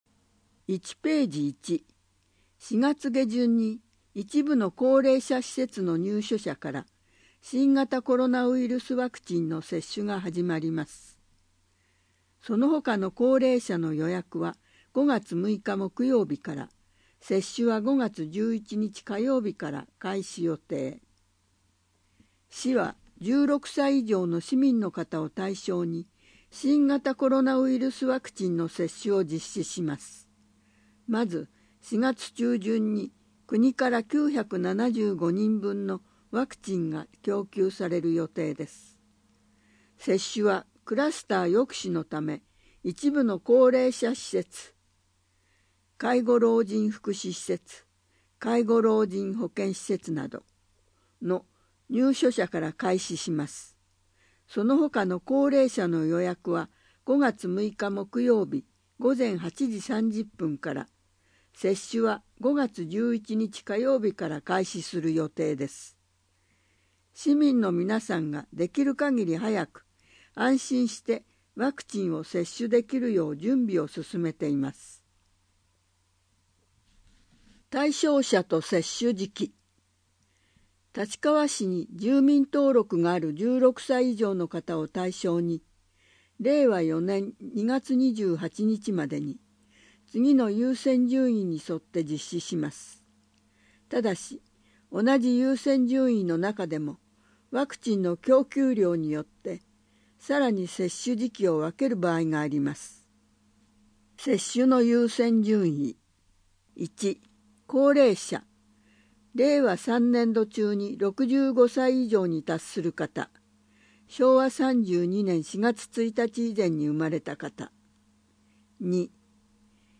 「広報たちかわ（PDF版）」及び「声の広報（音声版）」は、ページ下部の「関連ファイル」をご覧ください。